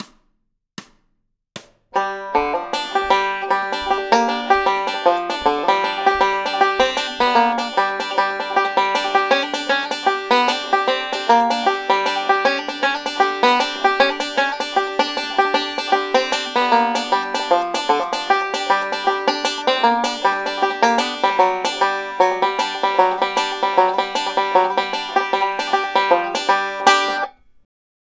classic solo